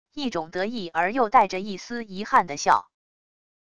一种得意而又带着一丝遗憾的笑wav音频